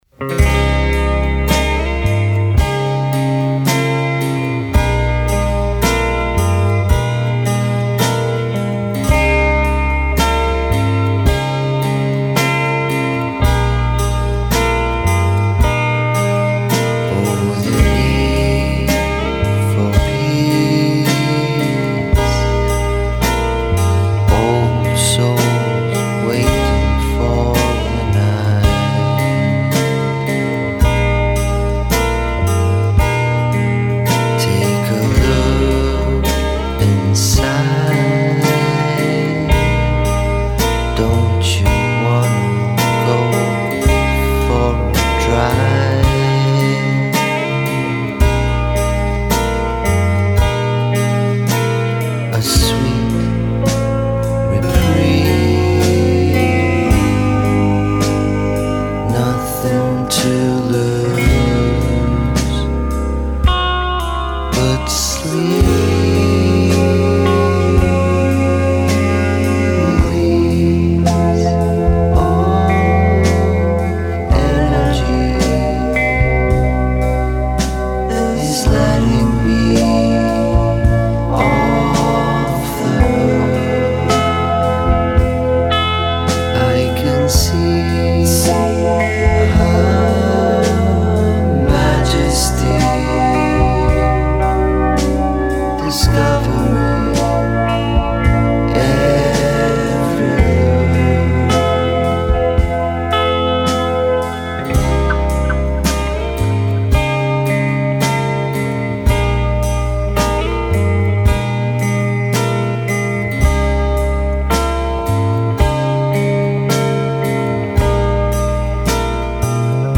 Long, slow and elegant
low key, low register vocals